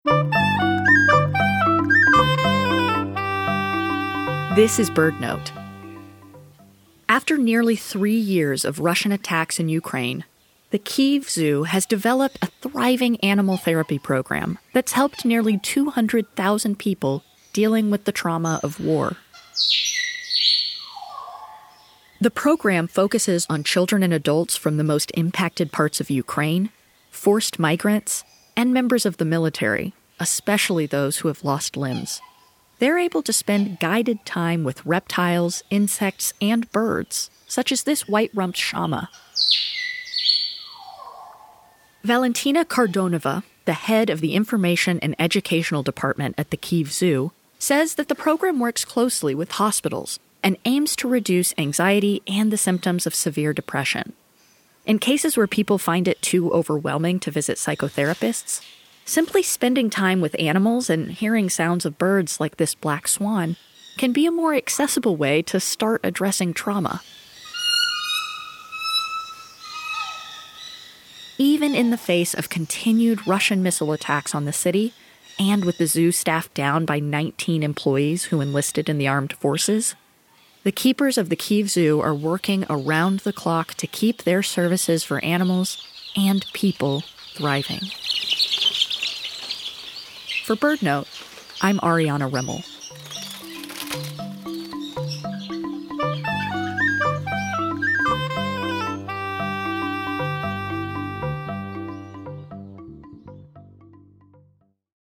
Shortly after the Russian invasion of Ukraine in February 2022, zookeepers at the Kyiv Zoo shared audio recordings from the animal enclosures with BirdNote. The Zoo staff maintained care for the animals even as they slept in bomb shelters.